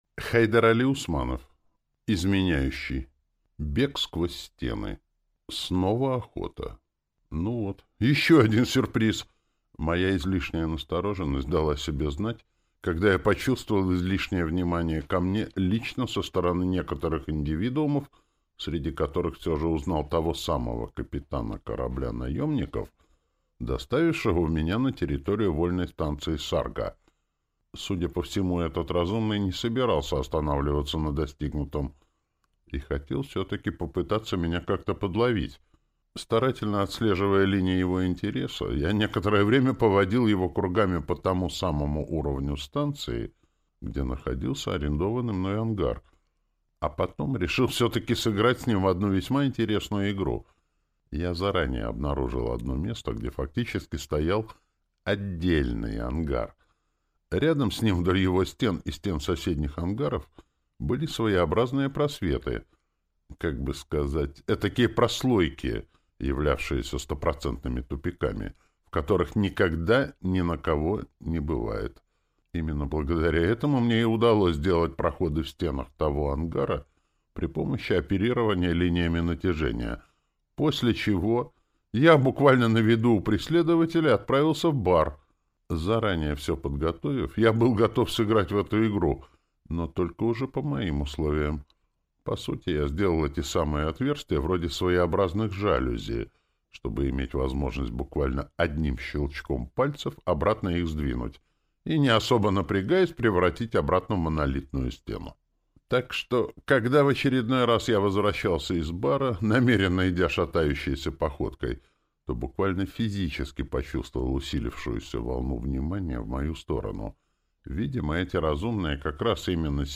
Аудиокнига Изменяющий. Бег сквозь стены | Библиотека аудиокниг
Прослушать и бесплатно скачать фрагмент аудиокниги